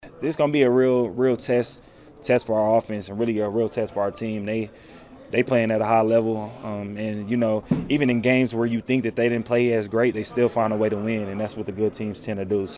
Packers running back Josh Jacobs on the challenge of facing the Broncos.